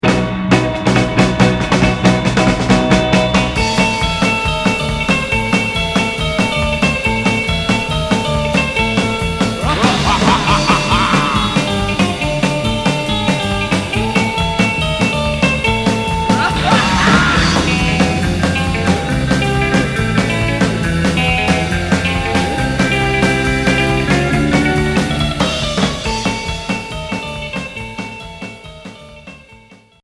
Surf